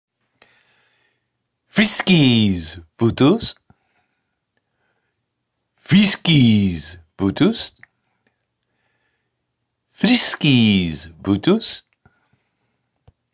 Southern French